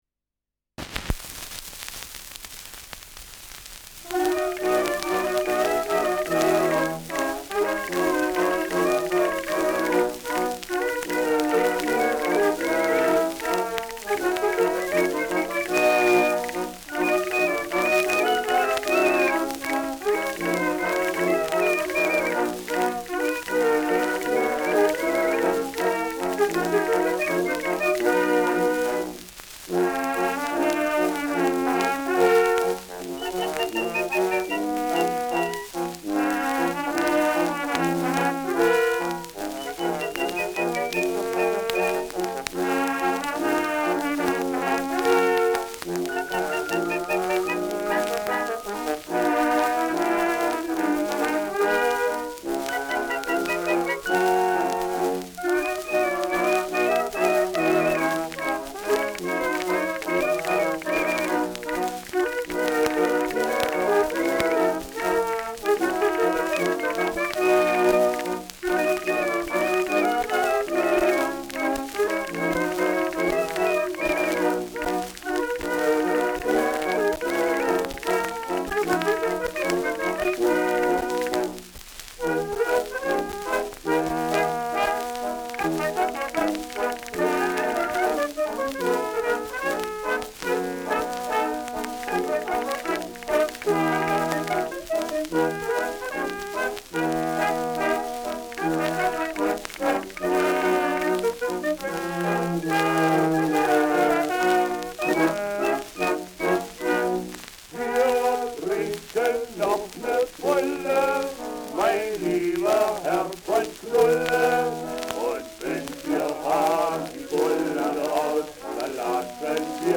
Rheinländer
Schellackplatte
Stärkeres Grundknistern